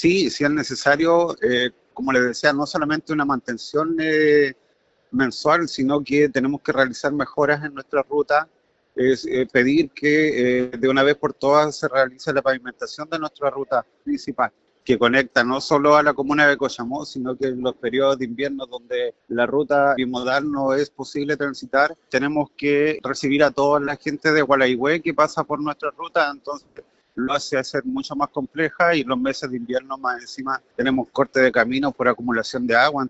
Previo a la reunión, el concejal Marco Morales planteó las principales urgencias que presenta este camino y la comuna en general.